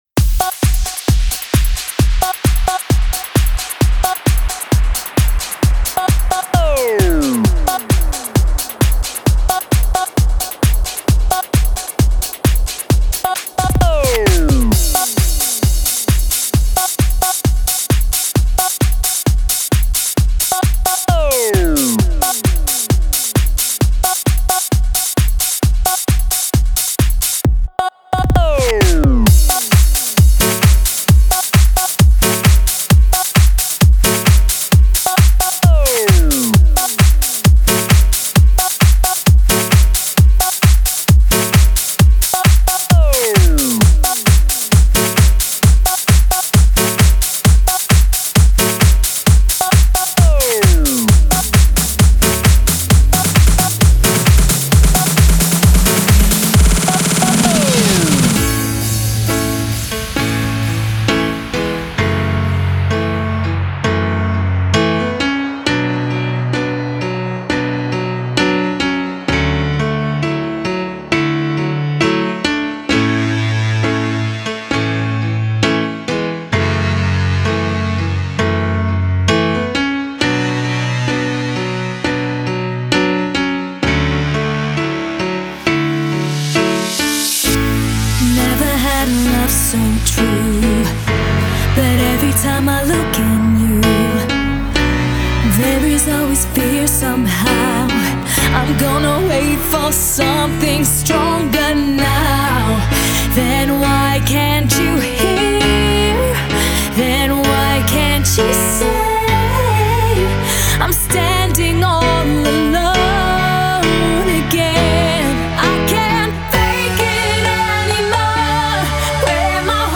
Жанр:Dance